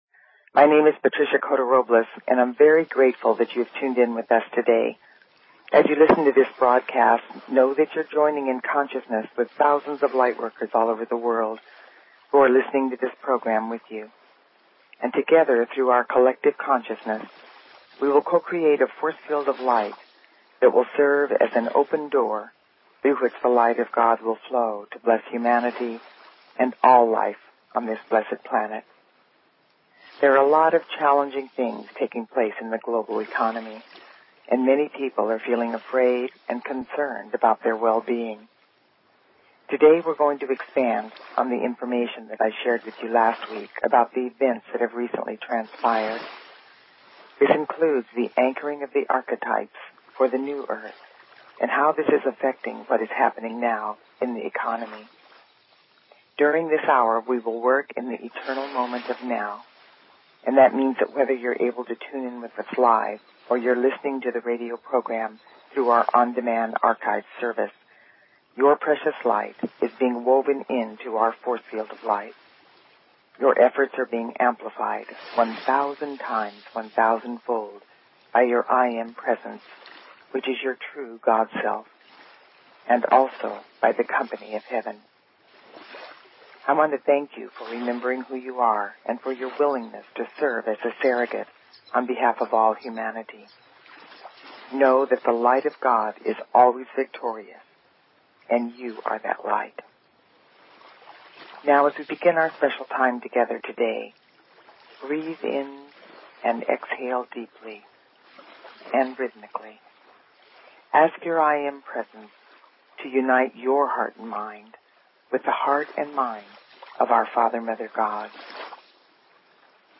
Talk Show Episode, Audio Podcast, Awaken_Your_Divine_Potential and Courtesy of BBS Radio on , show guests , about , categorized as